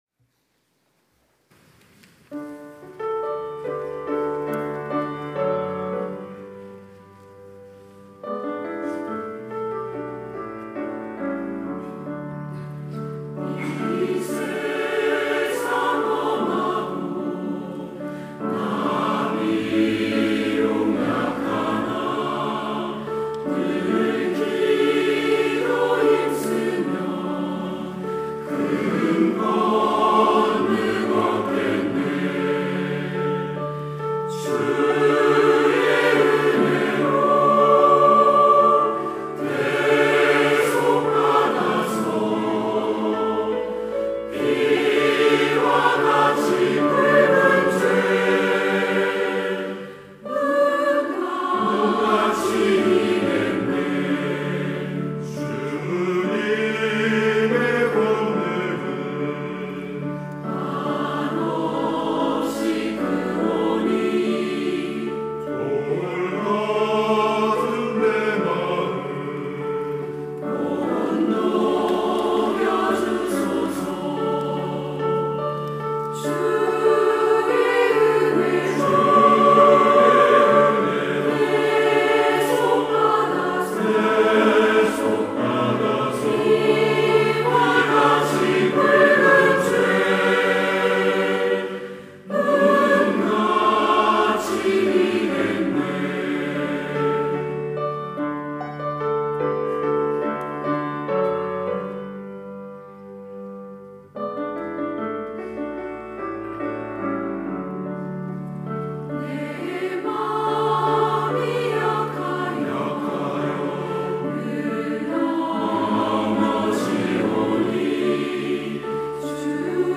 시온(주일1부) - 이 세상 험하고
찬양대